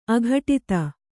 ♪ aghaṭita